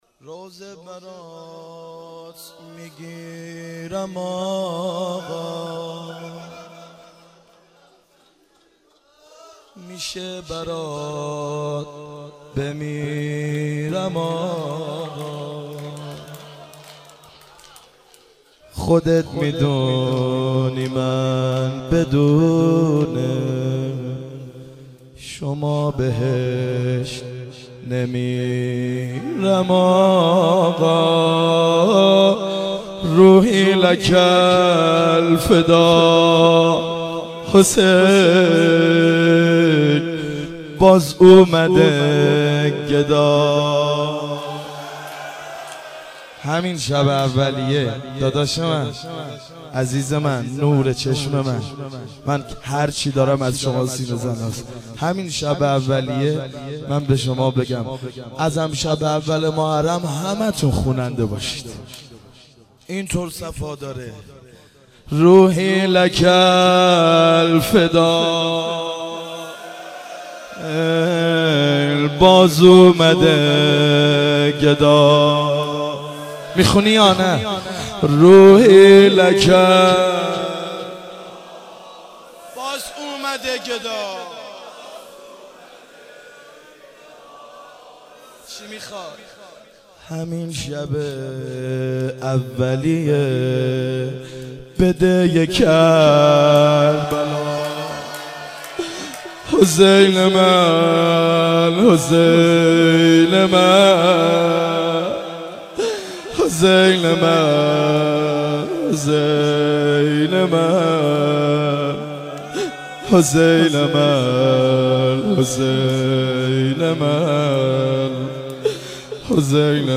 جلسه عزاداران حسین مظلوم علیه السلام